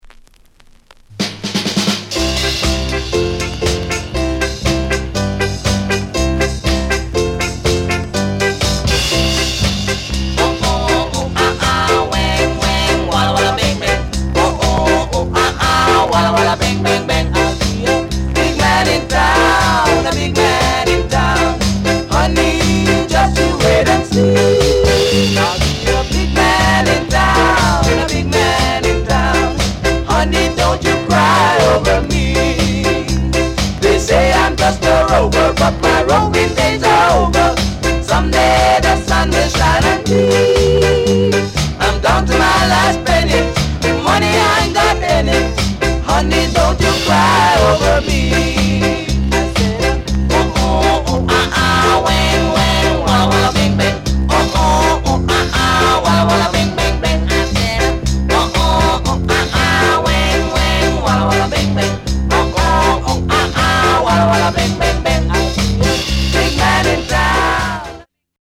SOUND CONDITION A SIDE VG
MASH UP SKA